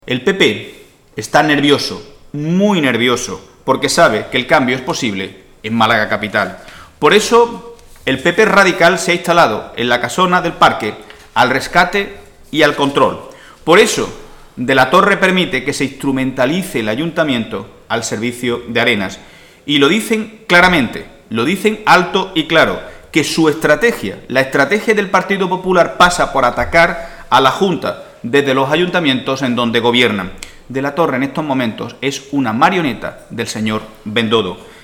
El secretario general del PSOE malagueño, Miguel Ángel Heredia, ha asegurado hoy en rueda de prensa junto al secretario de Organización, Francisco Conejo, que "el PP está nervioso porque sabe que el cambio es posible en Málaga capital"